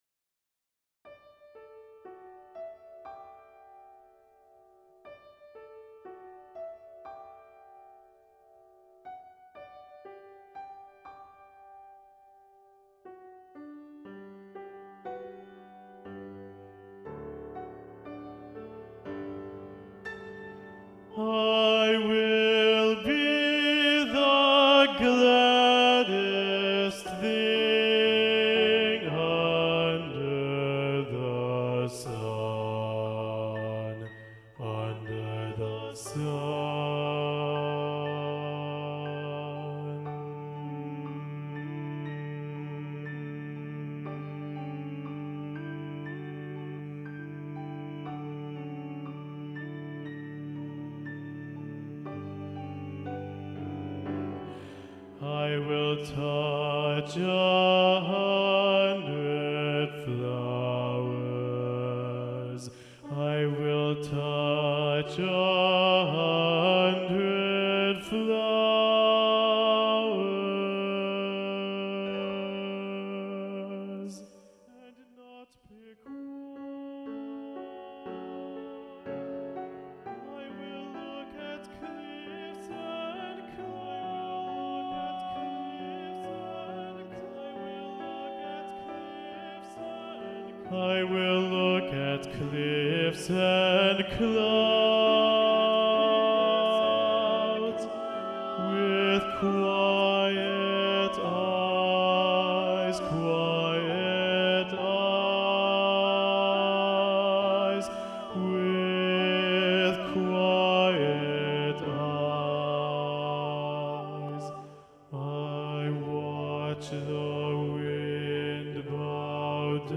Afternoon-On-a-Hill-Bass-1-Predominant-Eric-Barnum.mp3